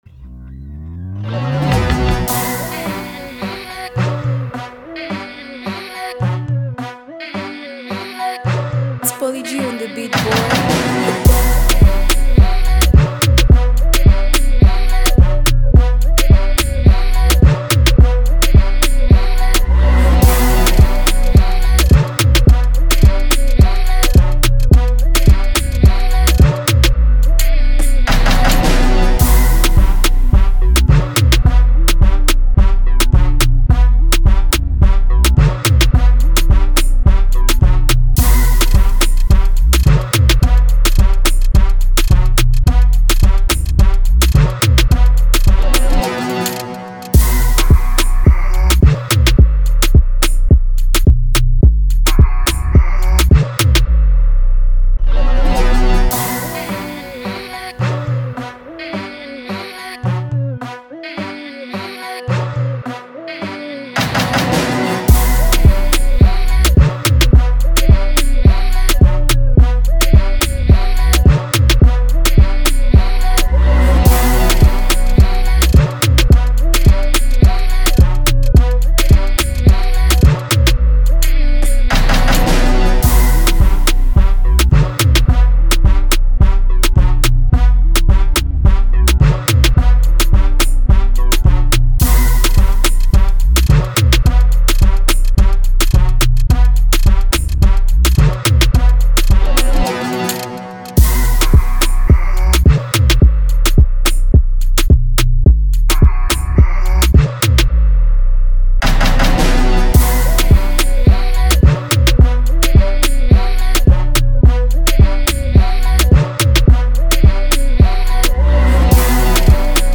Here the Free Dance Hall Beat.